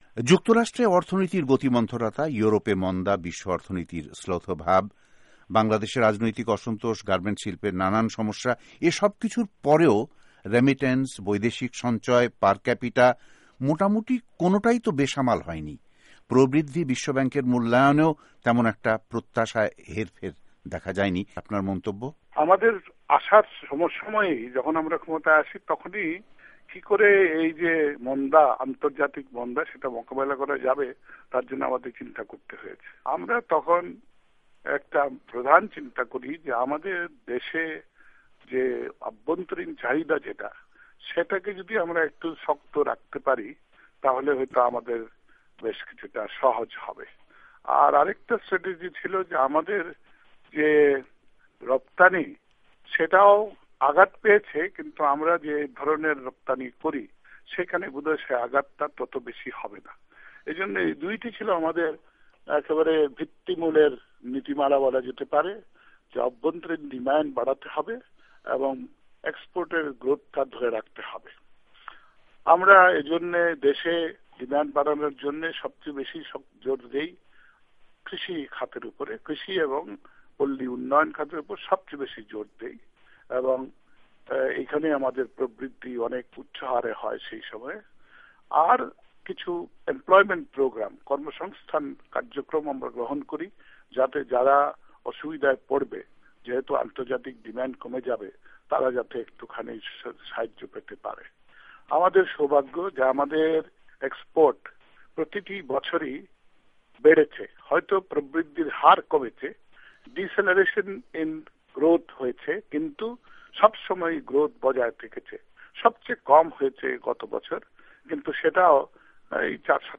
বাংলাদেশের অর্থমন্ত্রী আবুল মাল আব্দুল মুহিতের সাক্ষাত্কার। ওয়াশিংটন ডি সি‘র বিশ্বব্যাঙ্ক সদর কার্যালয় থেকে টেলিফোনে কথা বলেন বাংলাদেশের অর্থমন্ত্রী।